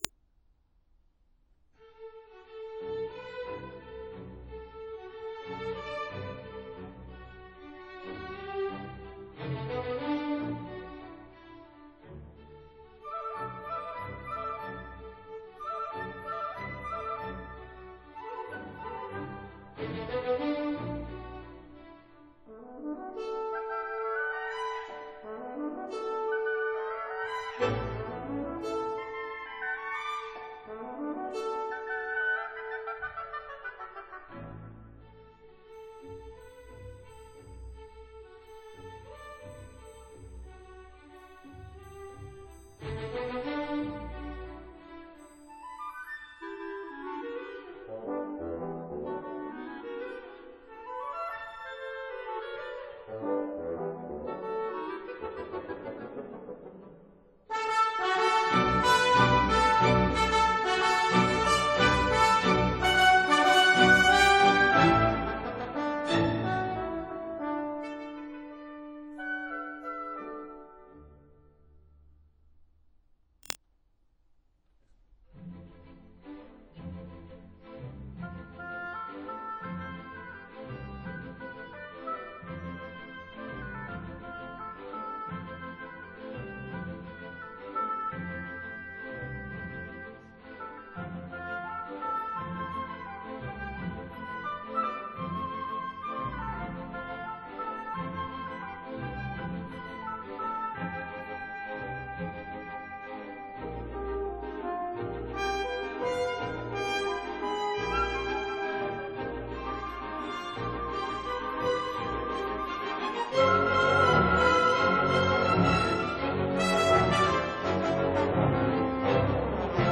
•(01) Symphony No. 5, for orchestra and choir